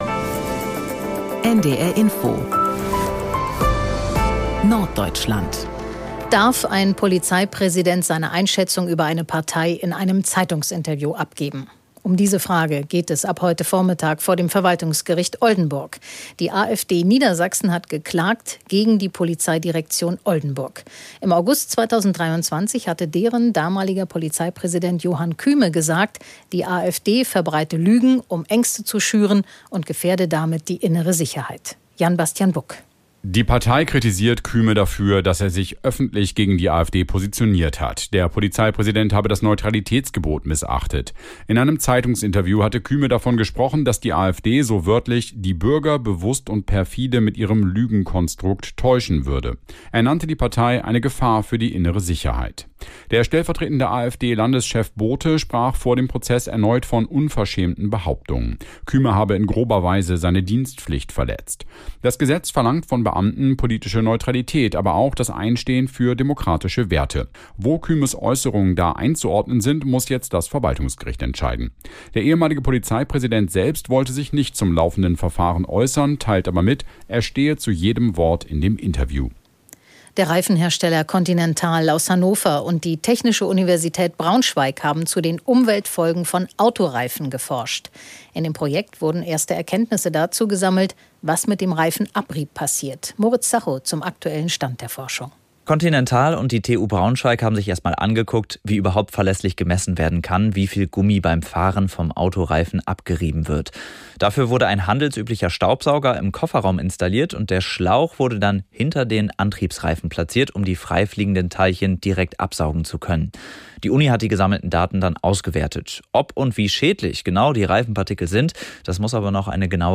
… continue reading 532 Episoden # Nachrichten # NDR Info # Tägliche Nachrichten